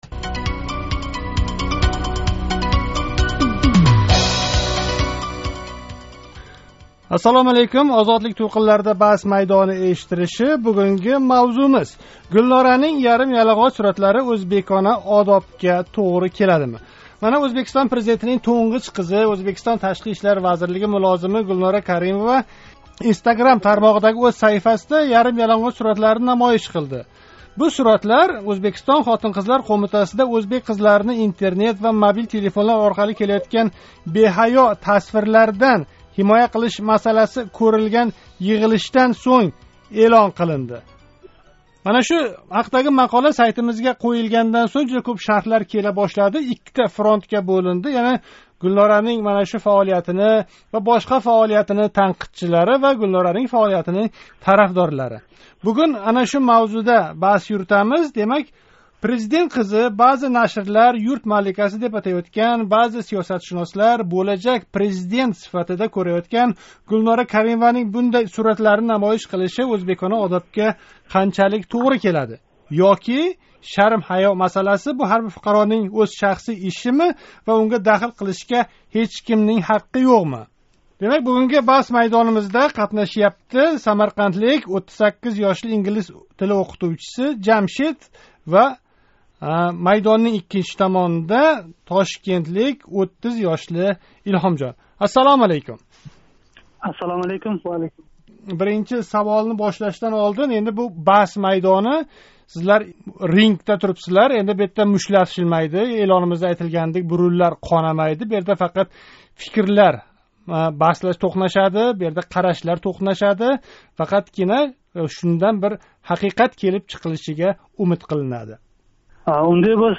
Озодликнинг навбатдаги "Баҳс майдони"да Гулнора Каримованинг Интернетда эълон қилган ярим яланғоч суратлари ҳақида баҳс юритилди.